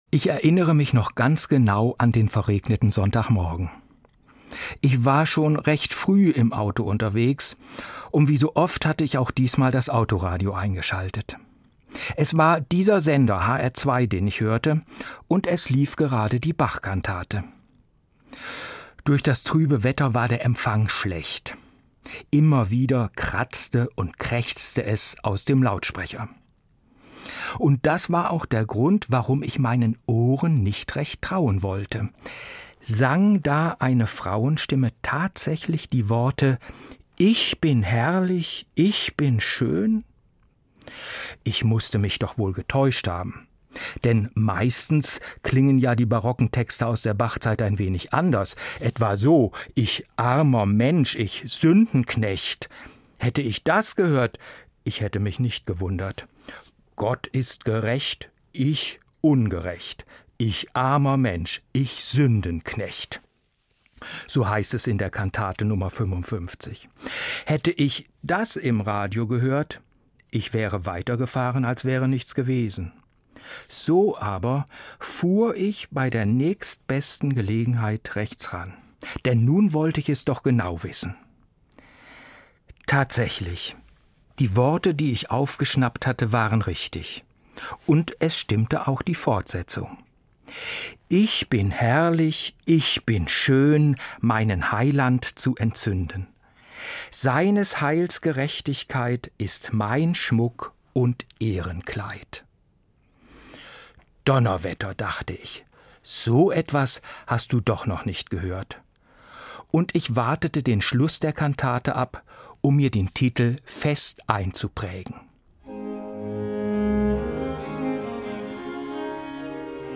Eine Sendung
Evangelischer Pfarrer